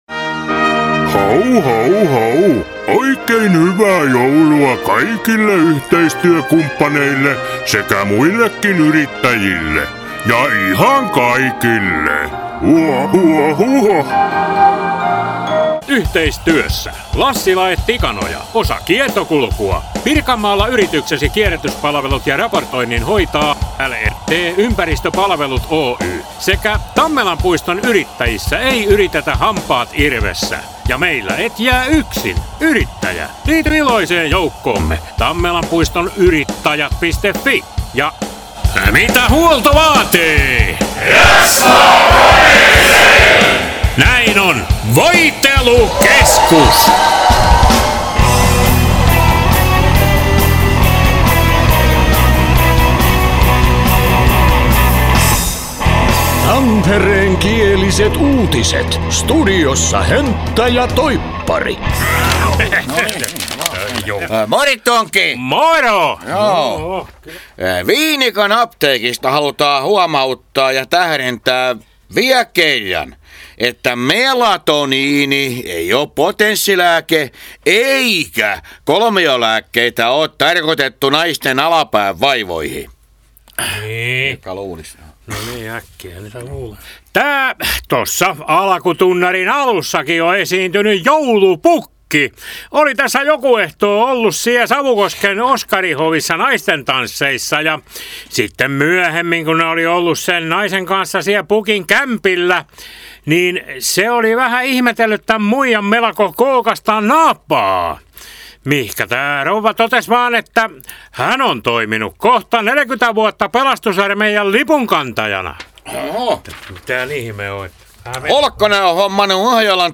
Tampereenkiäliset uutiset